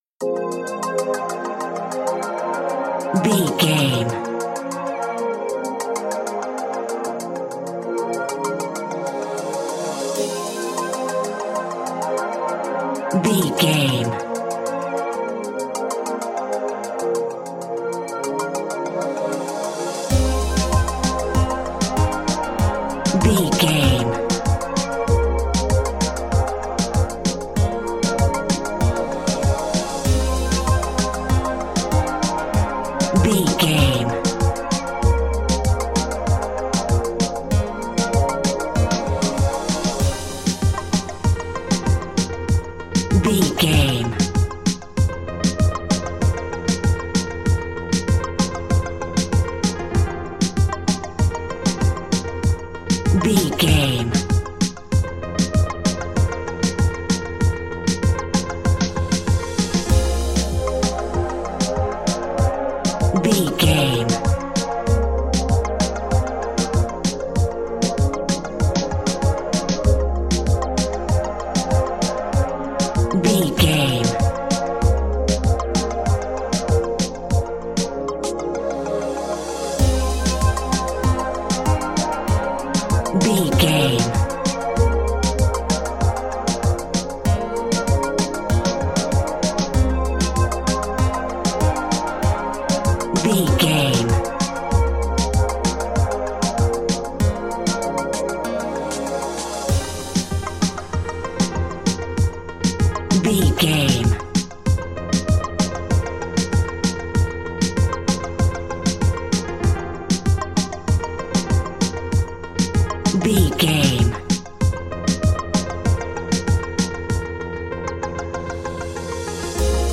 Classic reggae music with that skank bounce reggae feeling.
Aeolian/Minor
dub
laid back
chilled
off beat
drums
skank guitar
hammond organ
percussion
horns